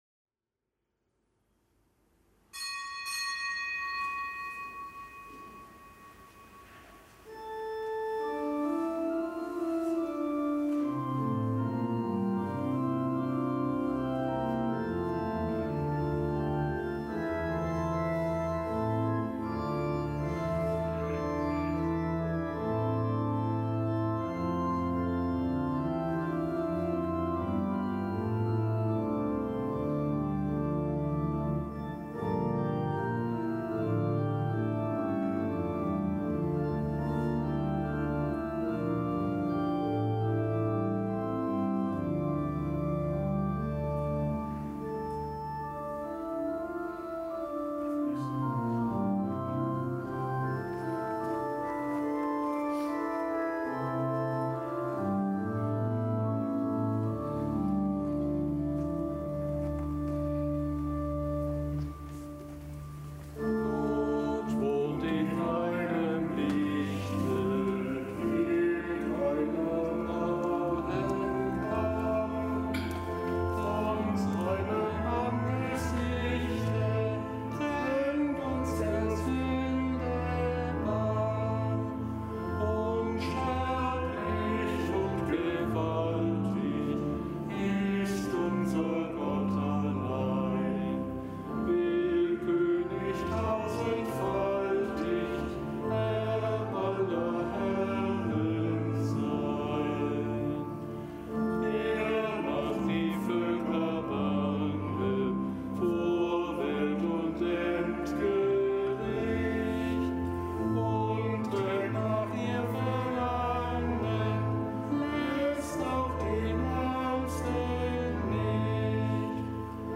Kapitelsmesse am Mittwoch der vierunddreißigsten Woche im Jahreskreis
Kapitelsmesse aus dem Kölner Dom am Mittwoch der vierunddreißigsten Woche im Jahreskreis, nichtgebotener Gedenktag des Heiligen Konrad und des Heiligen Gebhard, Bischöfe von Konstanz (RK); Zelebran